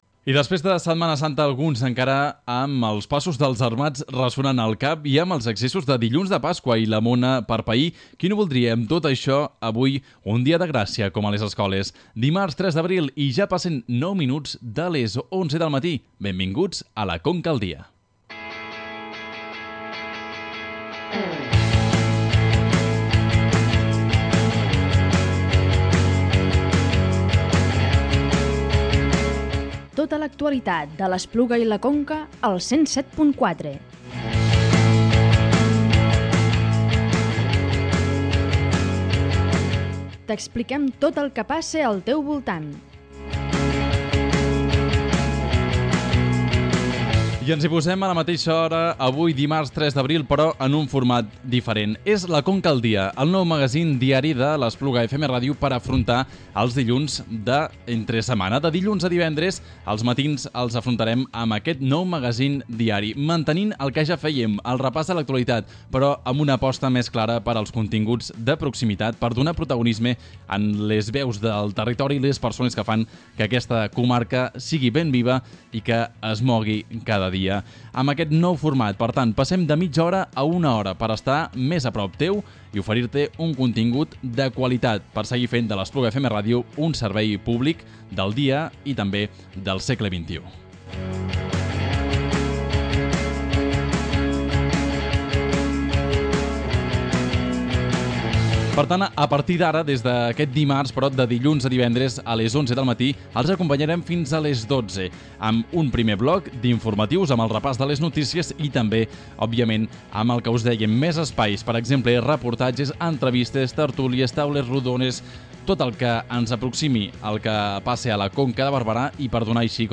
En el programa d’aquest dimarts fem un repàs de les notícies més destacades d’aquests dies a la Conca de Barberà, prestant especial interès als actes litúrgics de Setmana Santa. En la segona part, escoltem una tertúlia de luxe sobre el procés català.